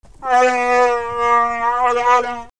It is not an easy language for humans and other humanoids to learn, as most of the sounds emanate as growls and howls from the back of the throat.
Wookiee 1
SHYRIIWOOK LANGUAGE SAMPLE SOUNDS